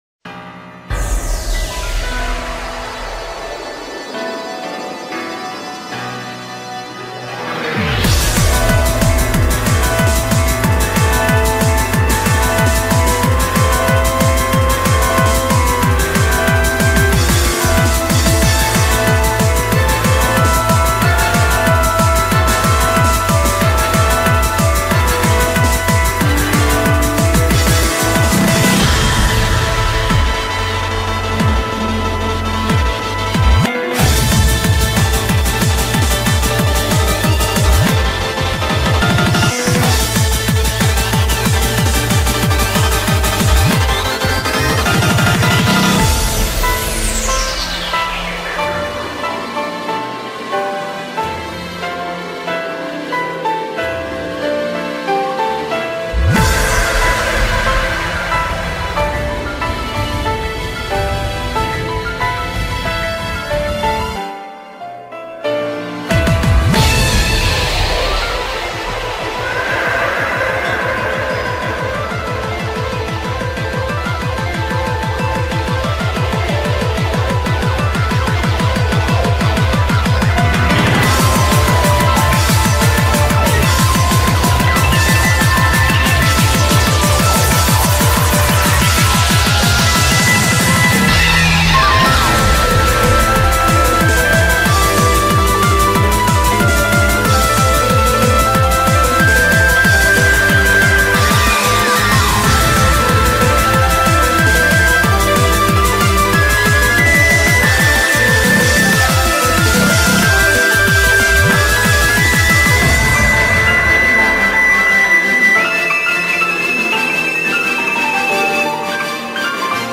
BPM83-370
Audio QualityPerfect (Low Quality)